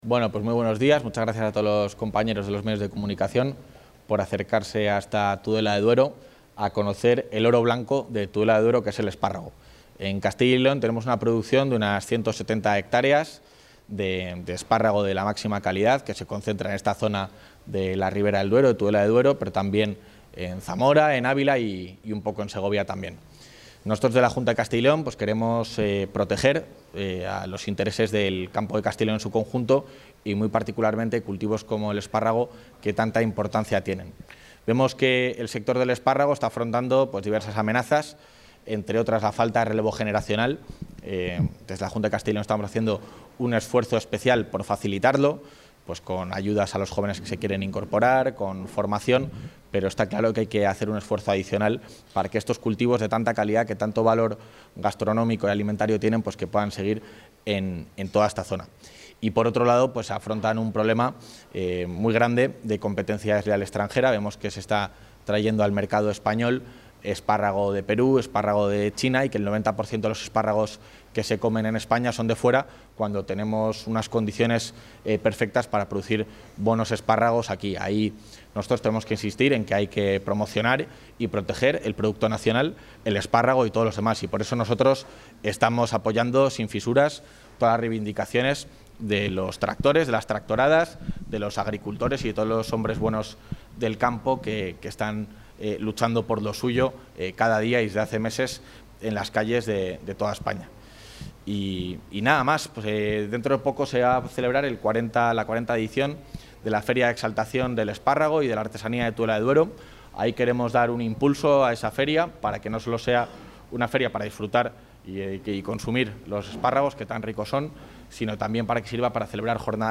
Declaraciones del vicepresidente.